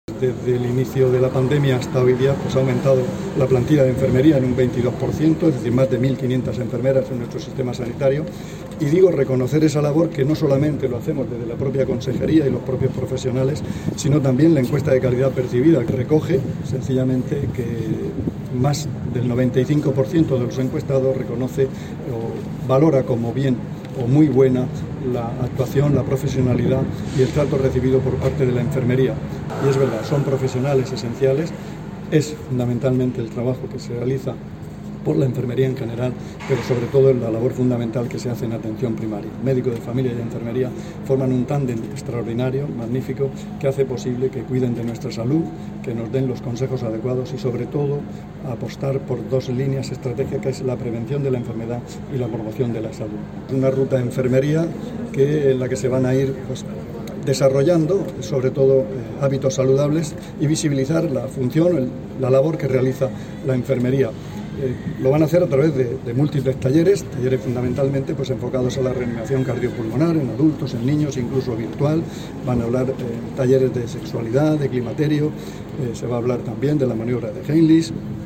Declaraciones del consejero de Salud, Juan José Pedreño, sobre la 'Ruta enfermera' [mp3]